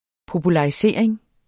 Udtale [ pobulɑiˈseˀɐ̯eŋ ]